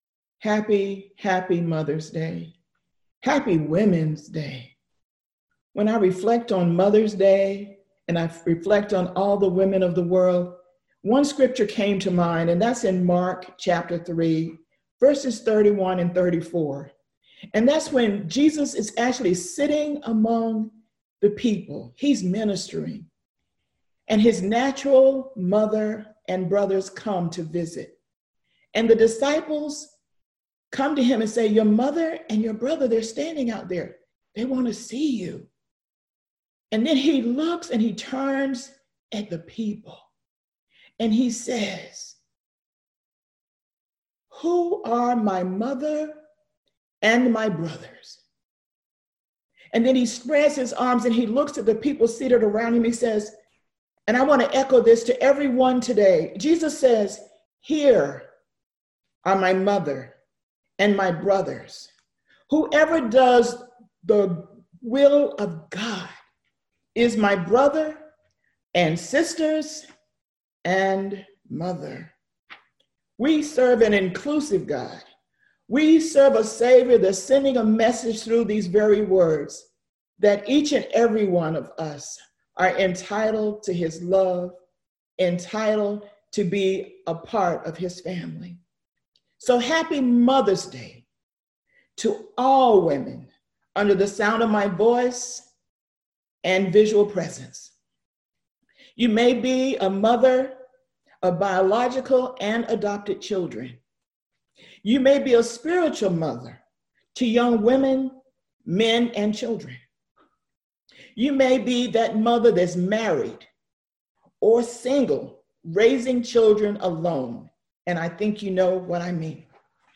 Enjoy our Mother's Day Service